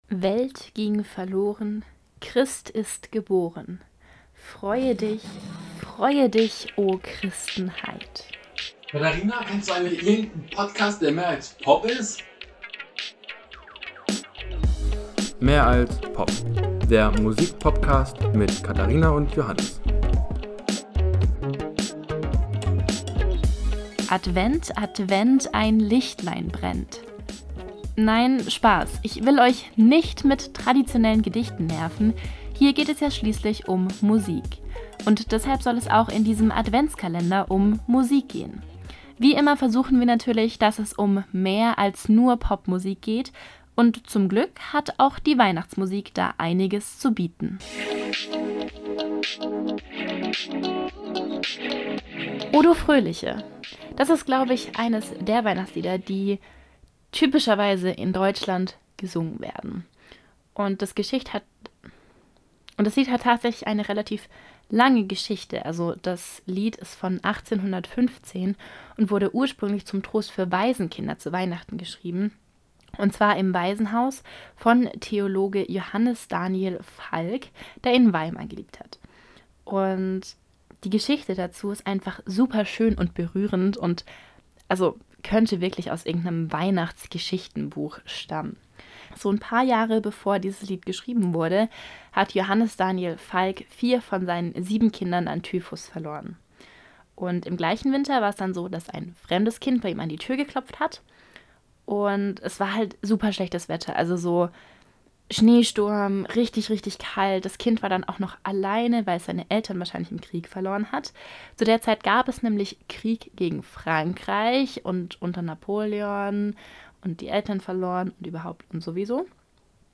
Die Musik für Intro und Outro ist von WatR.